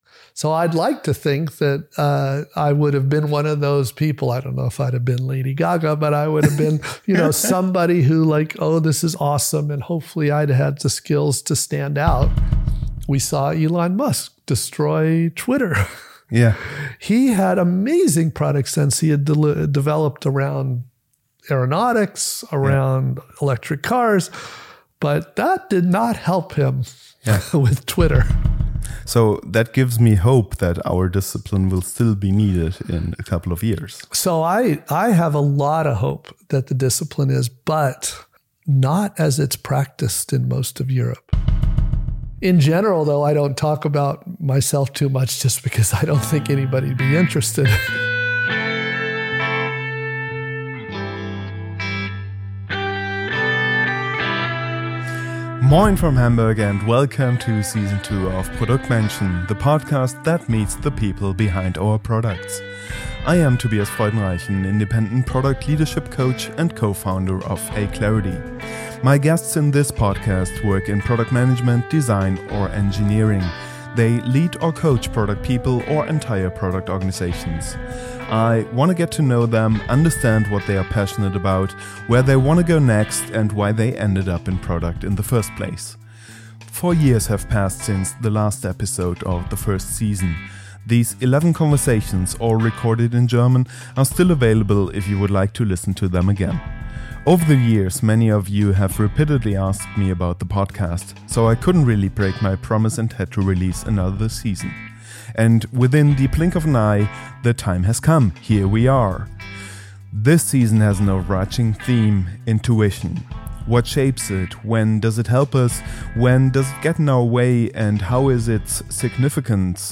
In Produktmenschen, I explore these questions in personal, honest conversations.